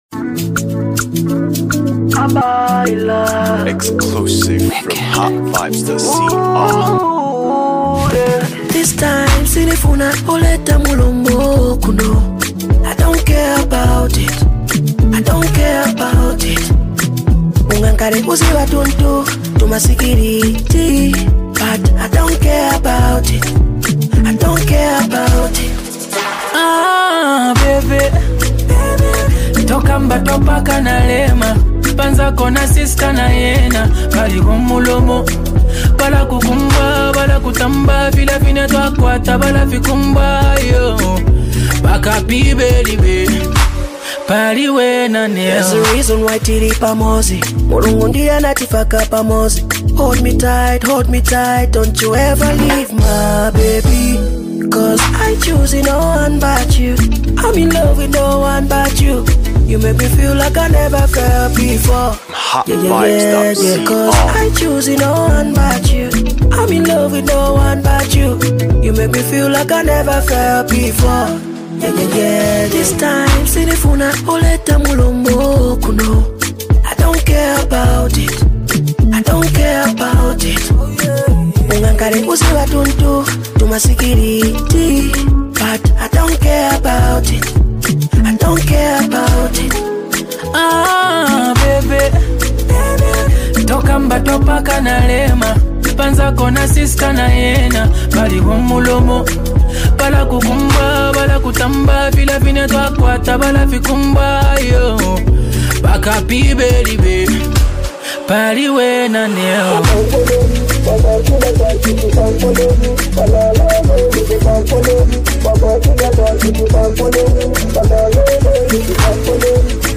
Zambian gifted voicelists